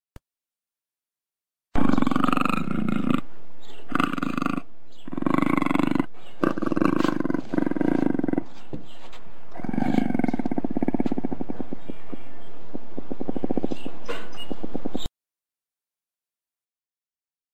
Звуки пумы горного льва